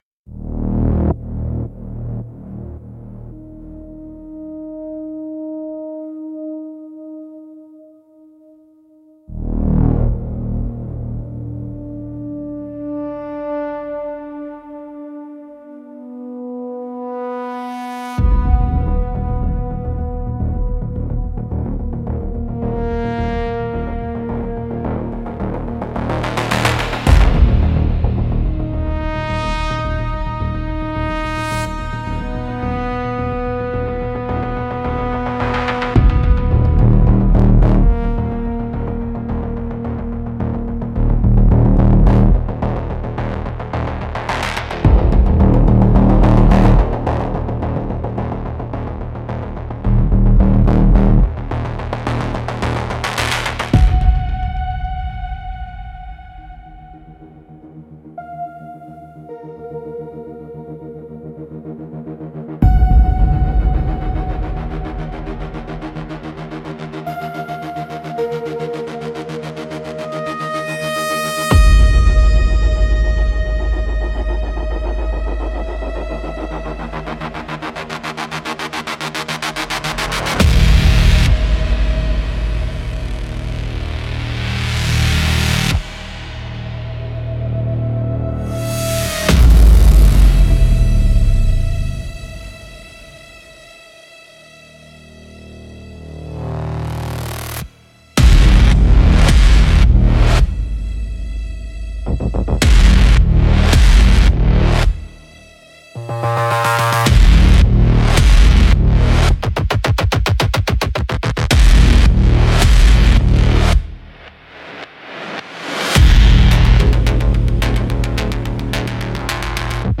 Instrumental - Cathode Hymn 5.16